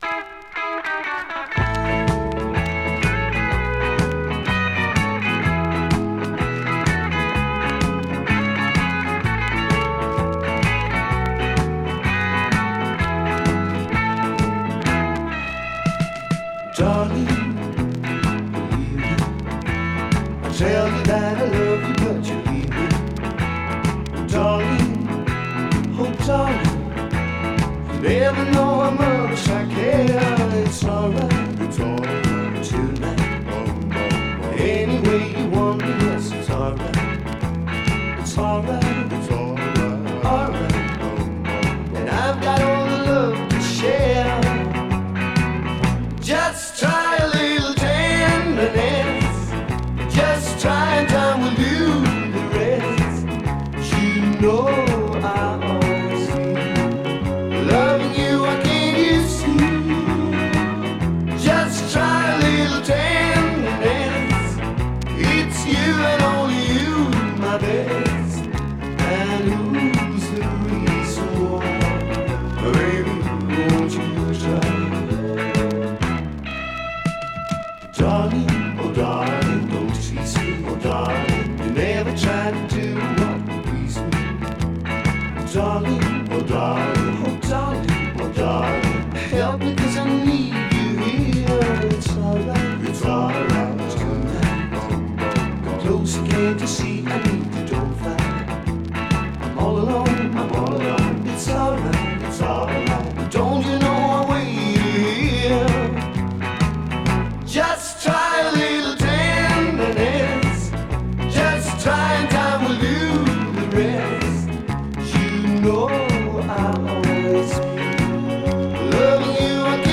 Vocals, Piano, Fender Rhodes 88,
Bass
Vocals, Drums, Percussion
Vocals, Guitars, Mandolin